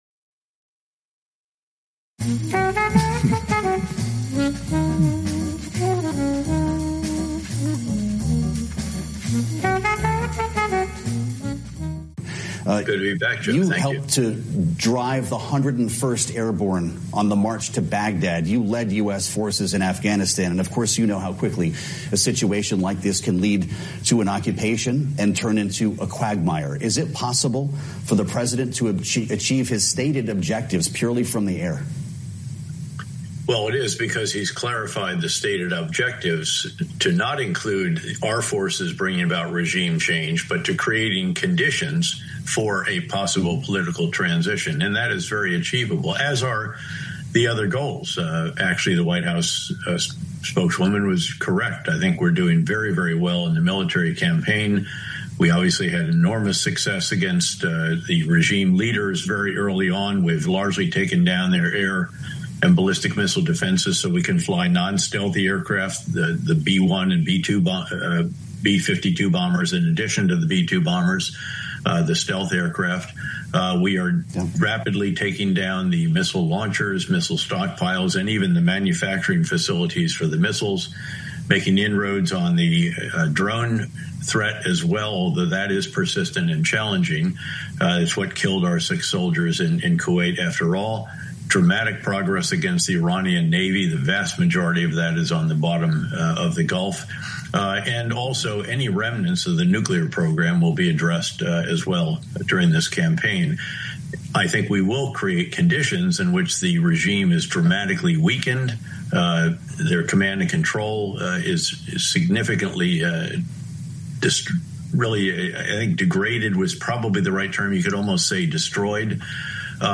ژنرال پترائوس، رئیس پیشین سیا، و فرمانده لشگرکشی به عراق و اشغال آن کشور، در مصاحبه با بلومبرگ، 6 مارس 2026، از حمله آمریکا به ایران دفاع کرد و اظهار داشت که به نظر او این تهاجم می تواند به تضعیف جمهوری اسللامی انجامیده، و ترامپ به اهداف اولیه خود برسد. وی برخلاف بسیاری از تحلیلگران معتقد است که ترامپ طبق یک برنامه از پیش مشخص شده وارد این پروژه شده است.